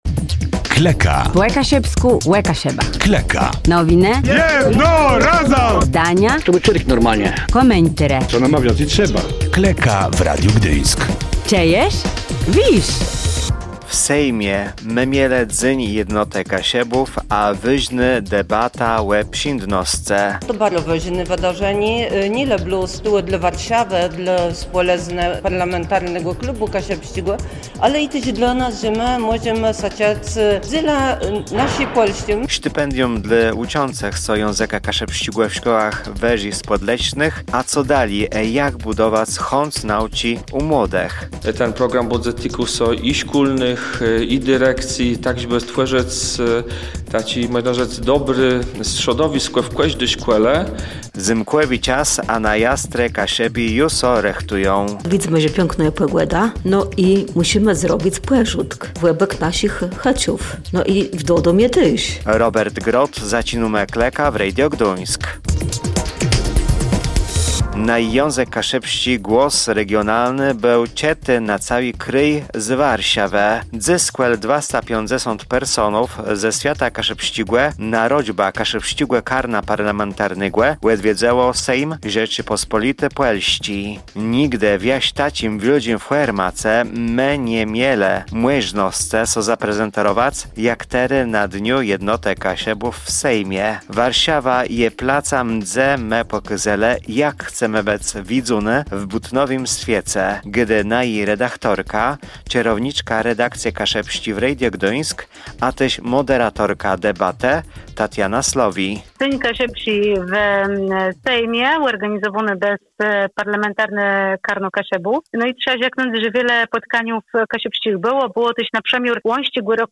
W najnowszym wydaniu audycji „Klëka” relacjonujemy wyjątkowe obchody zorganizowane w Warszawie przez Kaszubski Zespół Parlamentarny. Sejmowe korytarze wypełniły się ludowymi tańcami i pieśniami, ale równie ważnym elementem była merytoryczna debata o kulturze i tożsamości.